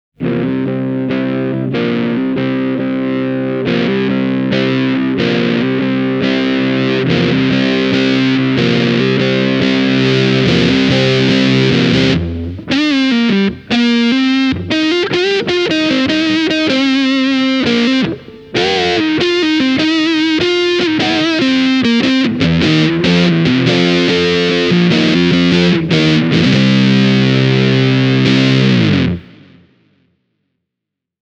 Tämä ääninäyte – kuten tämän jutun muutkin pätkät – on äänitetty Fender Stratocasterilla ja Blackstar HT-1R -putkikombolla:
JAM Red Muck reagoi esimerkillisesti kitaran volume-potikan kanssa, ja fuzz-efektin yleissoundi on täyteläisempi ja hieman kermaisempikin kuin monet muut fuzz-efektit. Tämä pedaali ei myöskään mene täysin tukkoon, silloin kun soittaa sointuja sen läpi.
Tämän audiopätkän alussa avaan hitaasti kitaran volume-potikan:
jam-pedals-e28093-red-muck.mp3